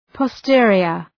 Προφορά
{pɒ’stıərıər}